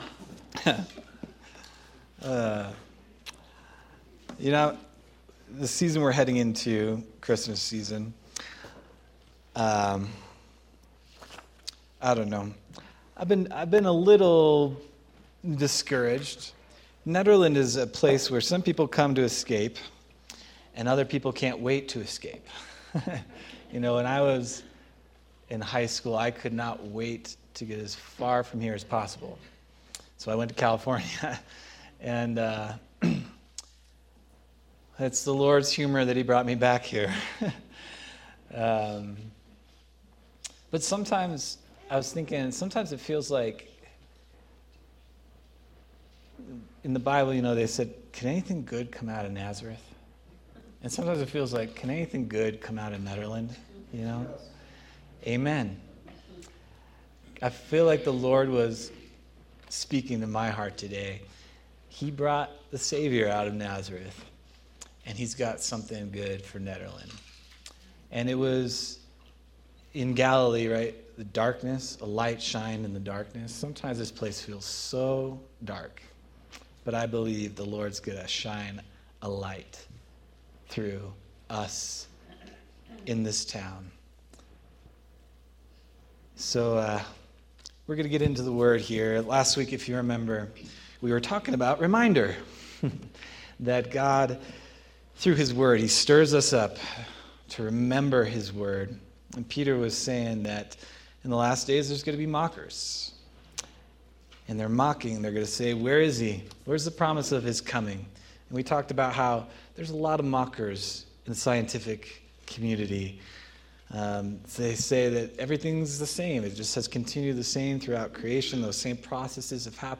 November 30th, 2025 Sermon